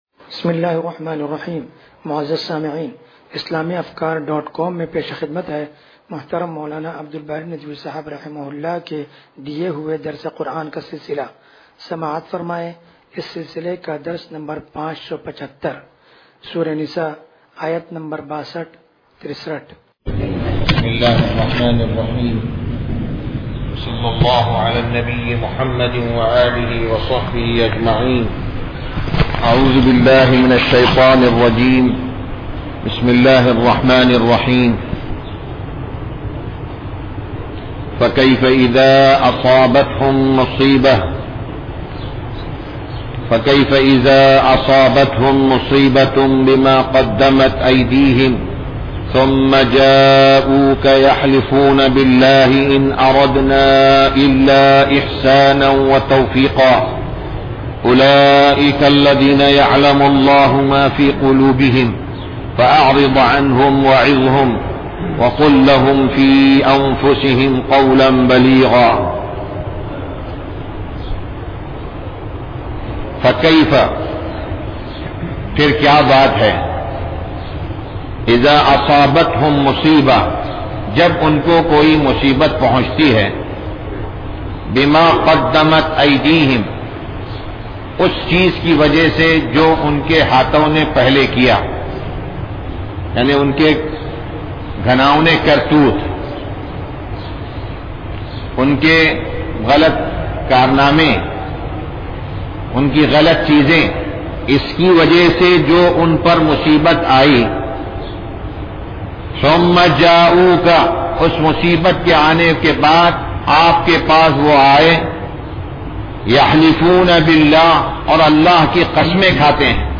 درس قرآن نمبر 0575